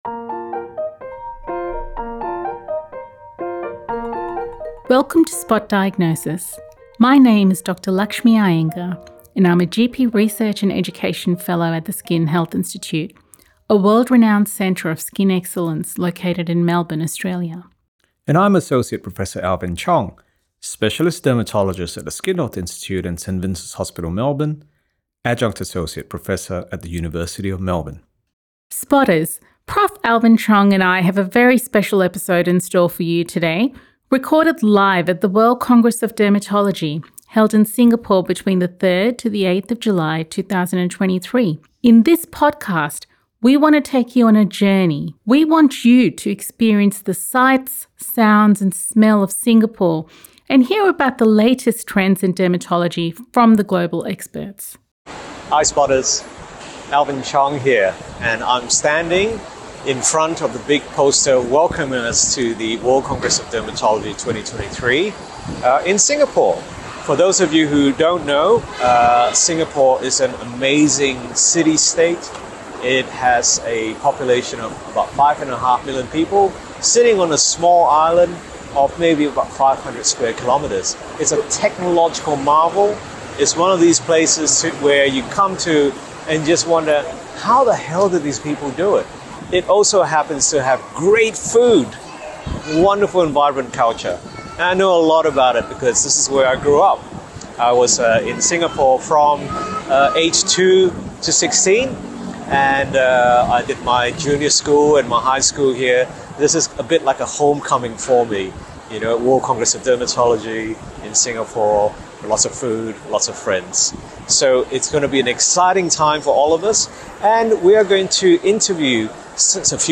This episode of Spot Diagnosis was recorded live at the Singapore World Congress of Dermatology in July 2023.